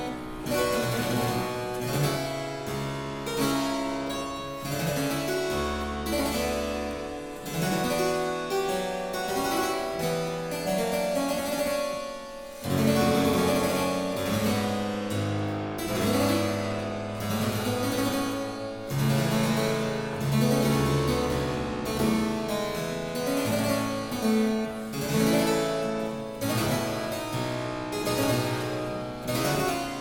clavecin